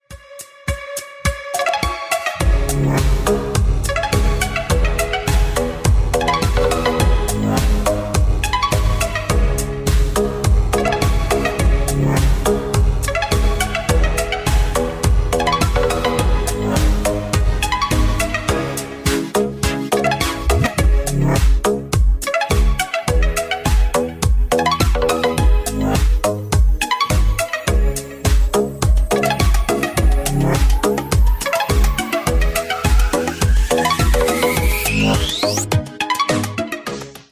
клубняк 2025